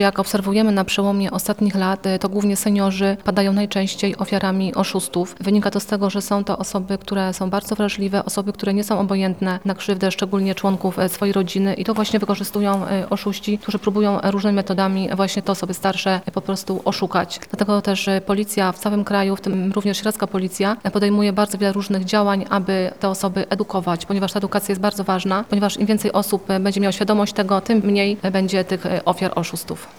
Debata społeczna dla seniorów w Sieradzu
Debata_Policja.mp3